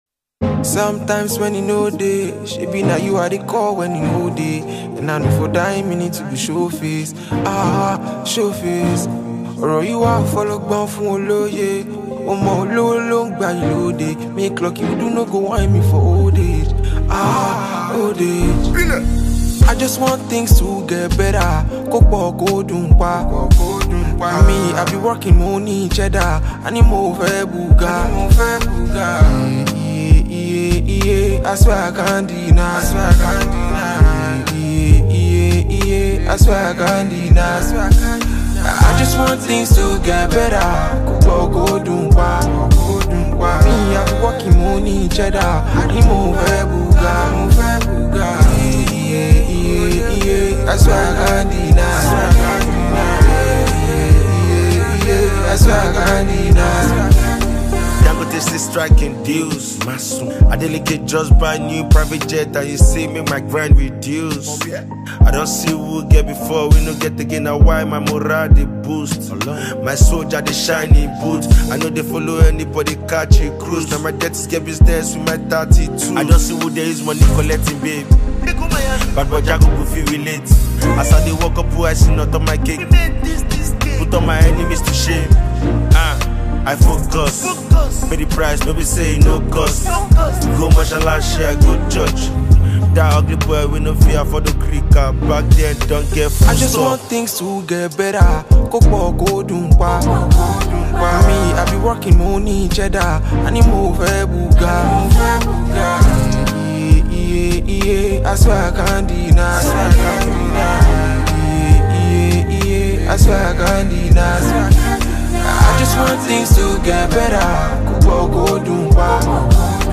Nigerian singer-songwriter